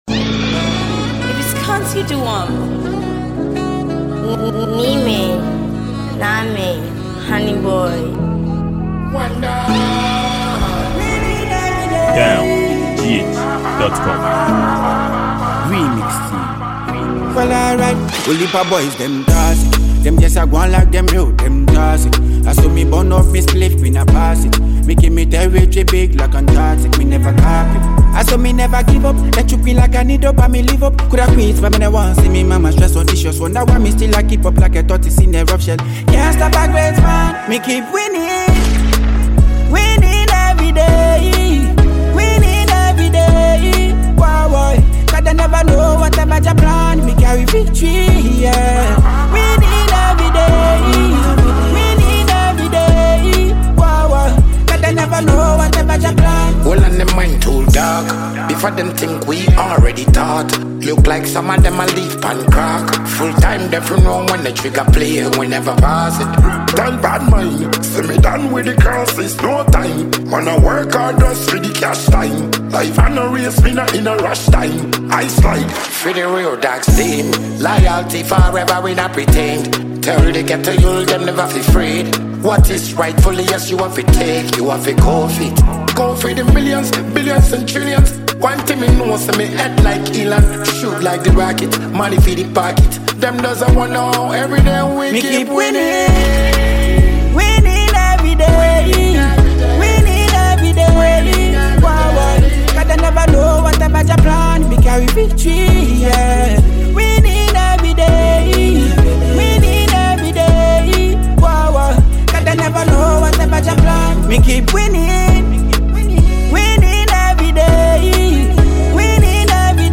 featuring Ghanaian dancehall musician